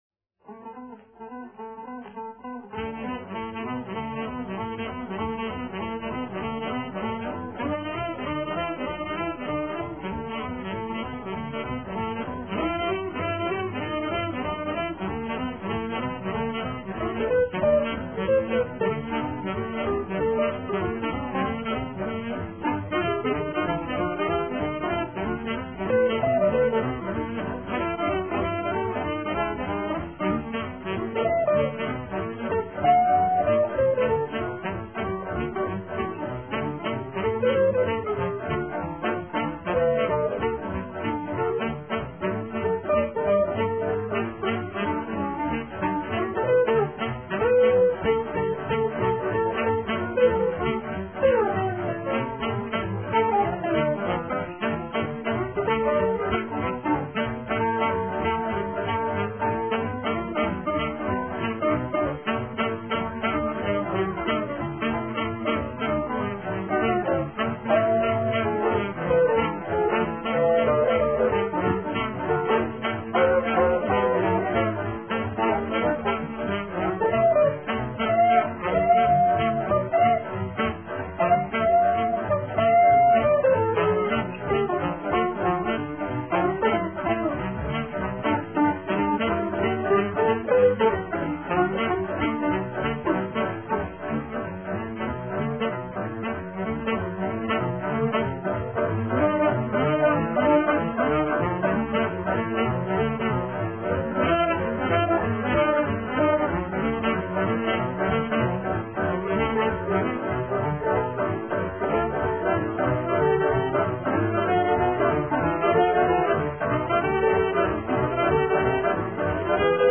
4-string tenor guitar